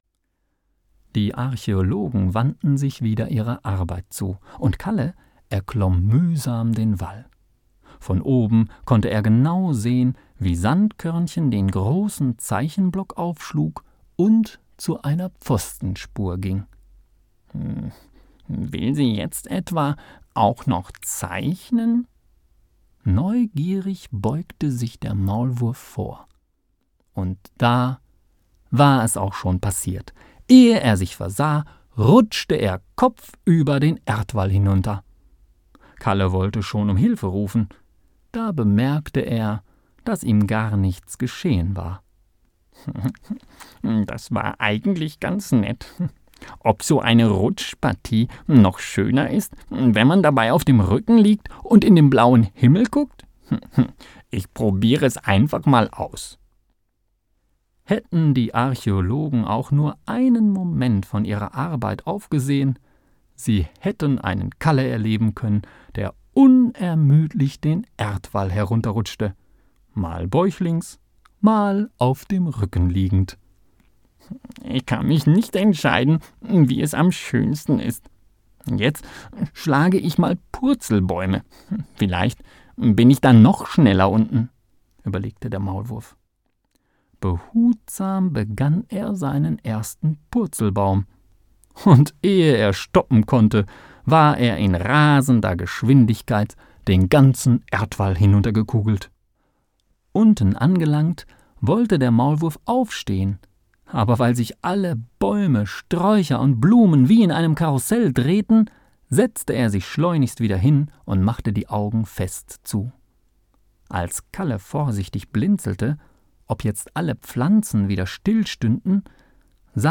Lies mir bitte das Kapitel vor...